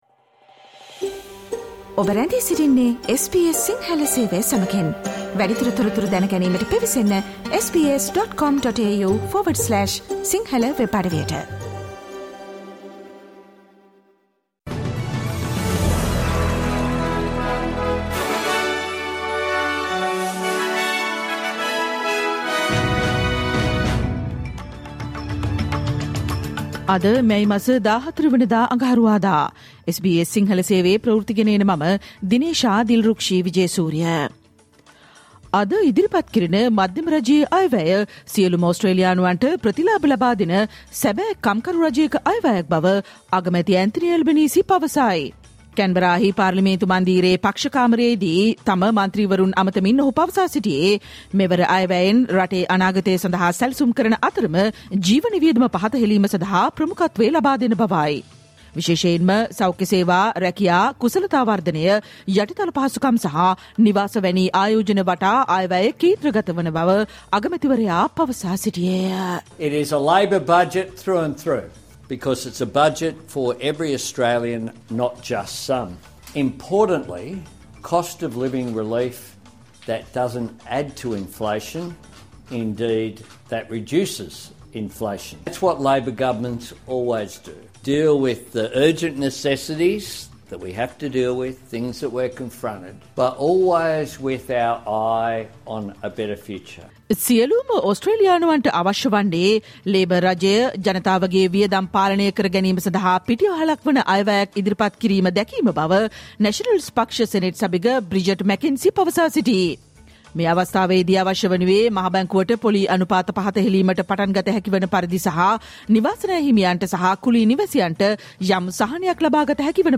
Australian news in Sinhala, foreign and sports news in brief - listen, Tuesday 14 May 2024 SBS Sinhala Radio News Flash